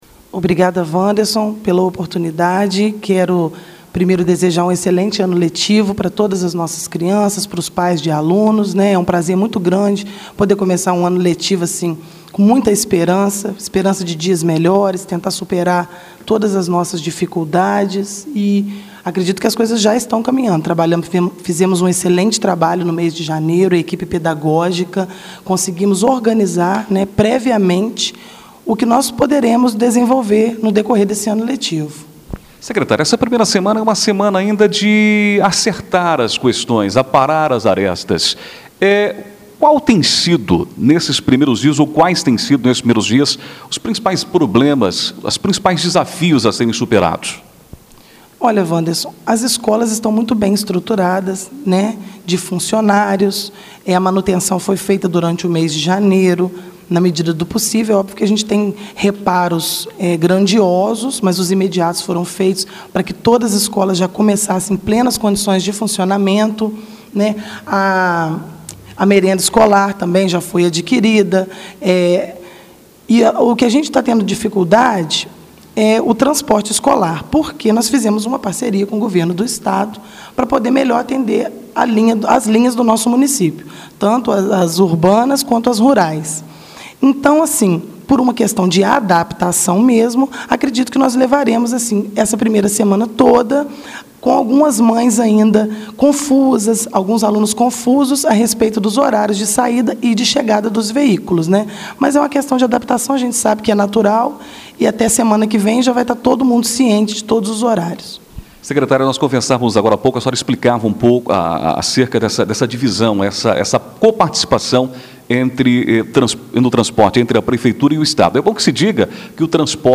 A Rádio Natividade levou ao ar na manhã desta terça-feira (07), entrevista com a secretária municipal de educação, Paula Ferreira (foto), que falou sobre a abertura do ano letivo, no dia anterior.
ENTREVISTA-SECRETARIA-DE-EDUCAÇÃO.mp3